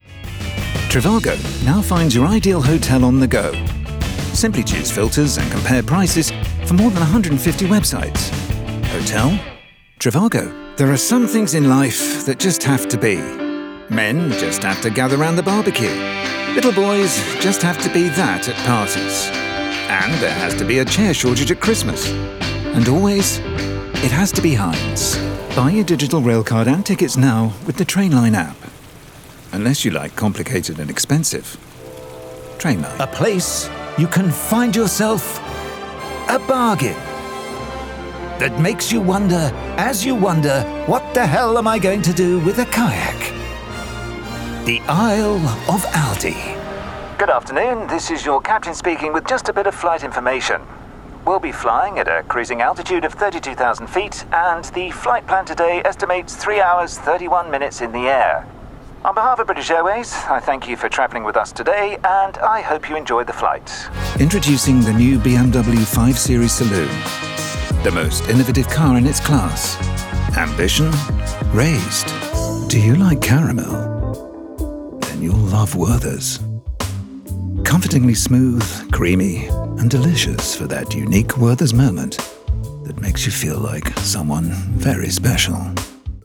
Male
Commercial Reel
RP ('Received Pronunciation')
Promo, Commercial, Upbeat